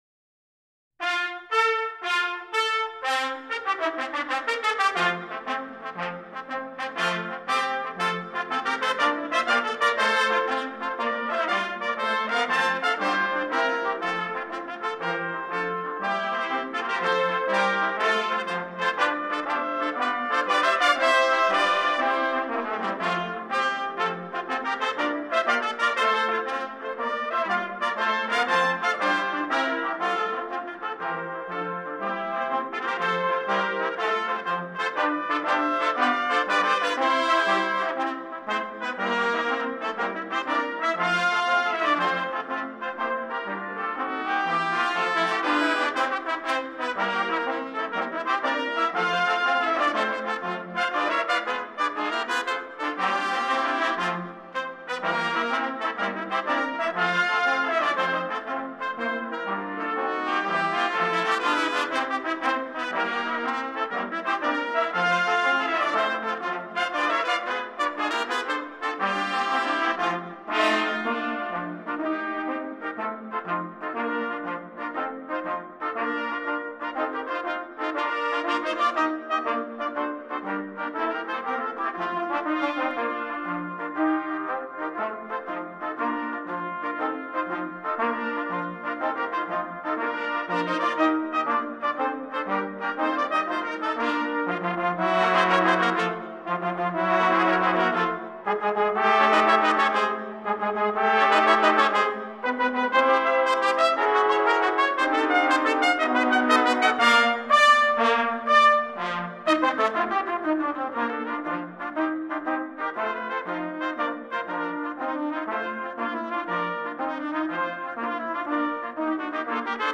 Written for 10 B-flat trumpets and set in 6/8
medium-up tempo march has a great march feel